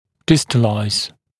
[‘dɪstəlaɪz][‘дистэлайз]дистализировать, перемещать в дистальном направлении